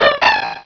pokeemerald / sound / direct_sound_samples / cries / bonsly.aif